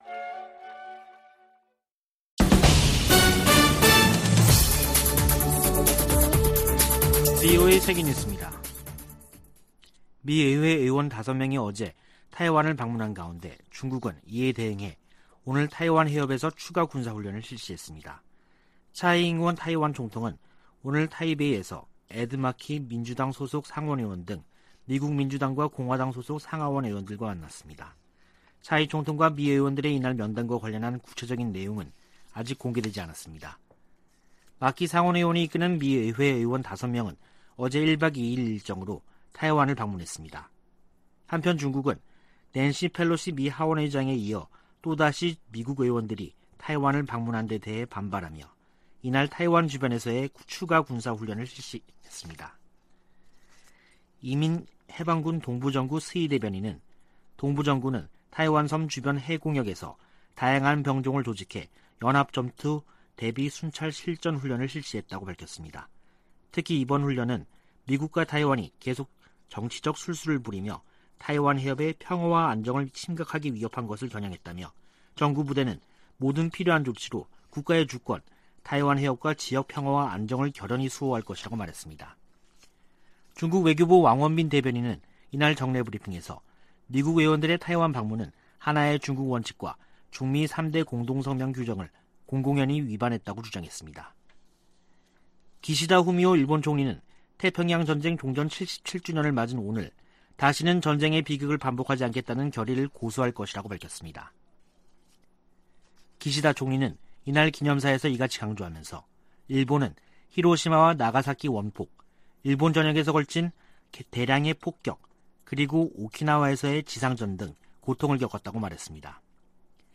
VOA 한국어 간판 뉴스 프로그램 '뉴스 투데이', 2022년 8월 15일 3부 방송입니다. 윤석열 한국 대통령이 광복절을 맞아, 북한이 실질적 비핵화로 전환하면 경제를 획기적으로 개선시켜주겠다고 제안했습니다. 중국의 ‘3불 1한’ 요구에 대해 해리 해리스 전 주한 미국대사는 중국이 주권국가에 명령할 권리가 없다고 지적했습니다. 중국이 낸시 펠로시 미국 하원의장의 타이완 방문을 구실로 도발적인 과잉 반응을 지속하고 있다고 백악관 고위관리가 규탄했습니다.